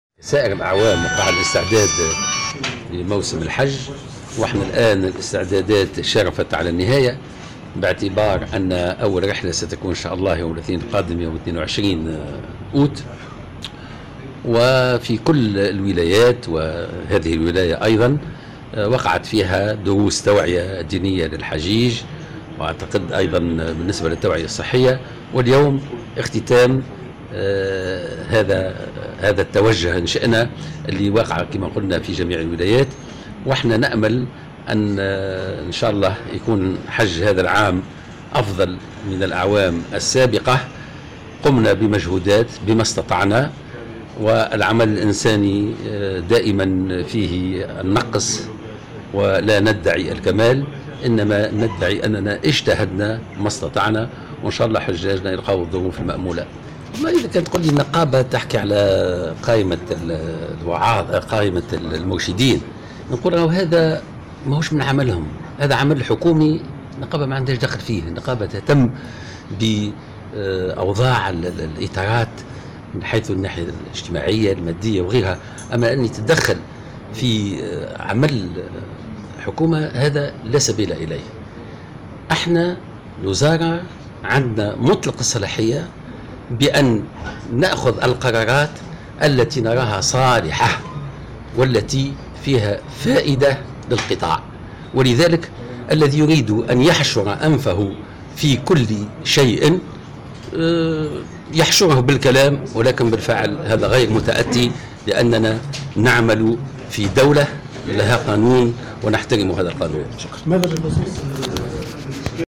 محمد خليل - وزير الشؤون الدينية تحميل المشاركة علي مقالات أخرى وطنية 27/08/2025 سوسة